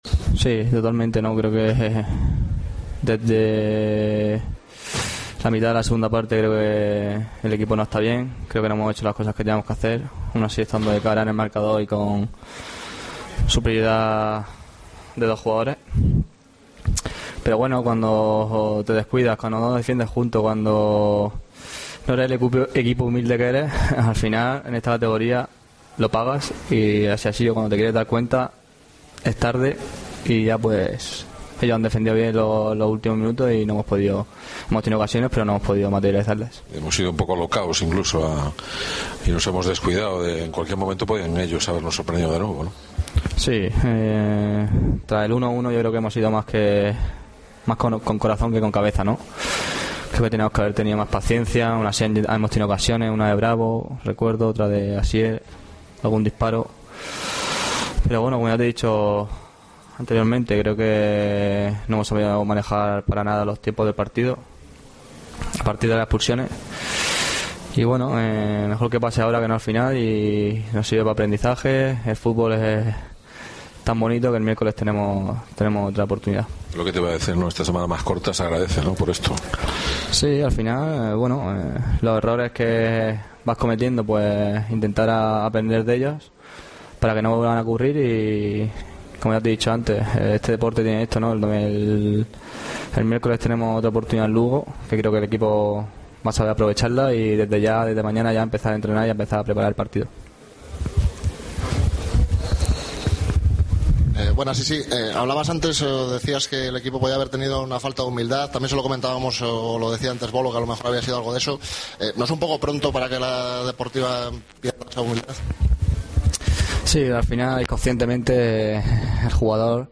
Escucha aquí las palabras de los dos jugadores de la Deportiva Ponferradina
Se han mostrado agridulces y resignados por no aprovechar la opción de ganar cuando se habían puesto por delante en el marcador y más tras haberse quedado el rival con dos jugadores menos sobre el terreno de juego por sendas expulsiones.